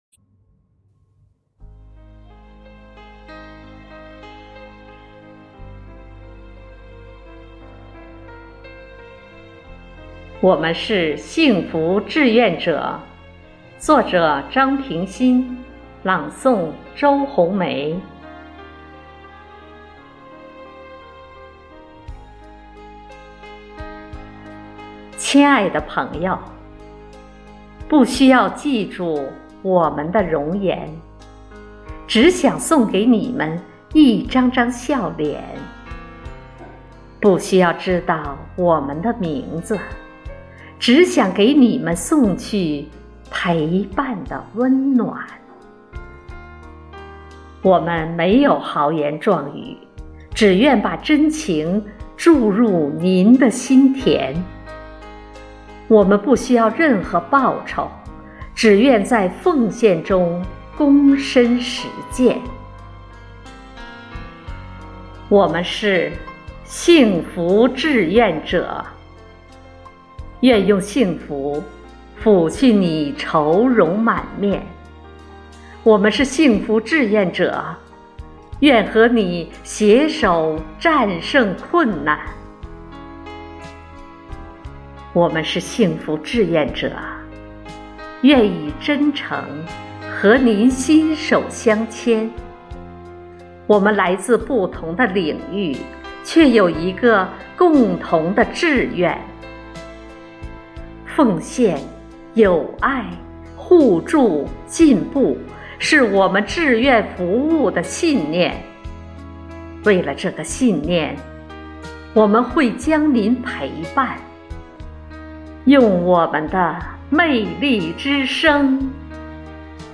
暨魅力之声支队第22场幸福志愿者朗诵会
独诵